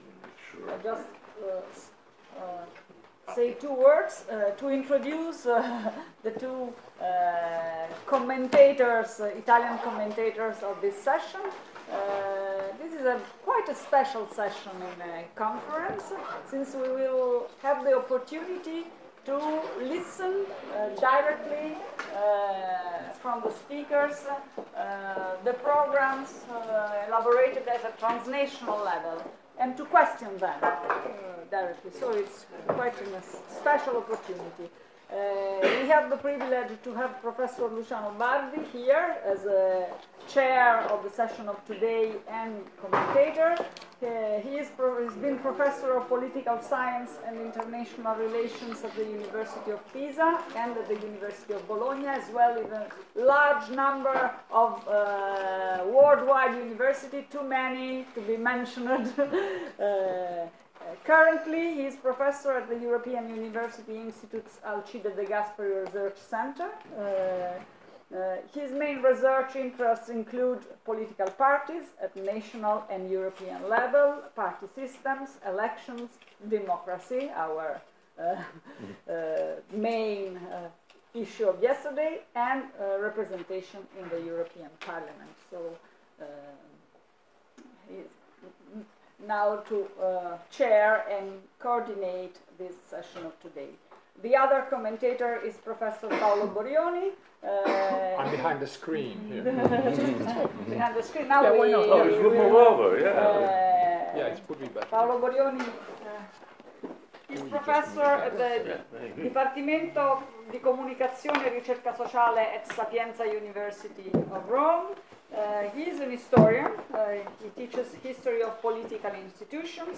Policy-makers-Tavola-rotonda.mp3